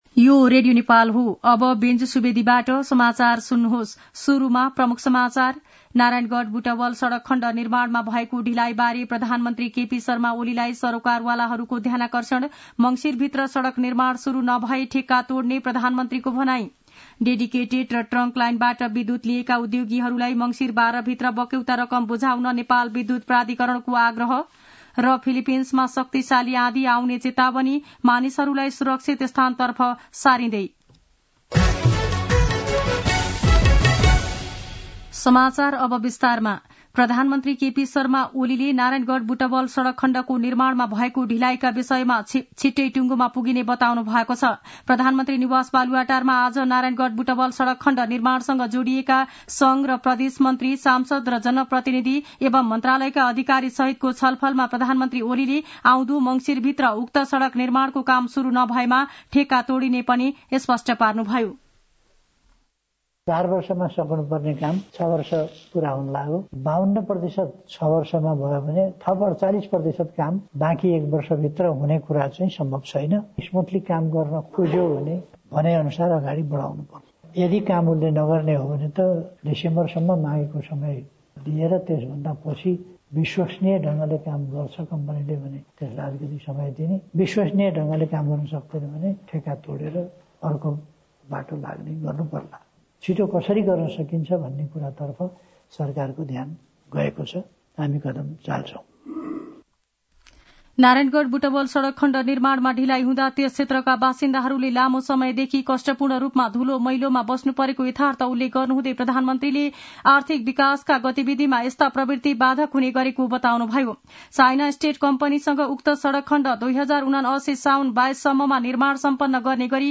दिउँसो ३ बजेको नेपाली समाचार : ३० कार्तिक , २०८१
3-pm-nepali-news-1-1.mp3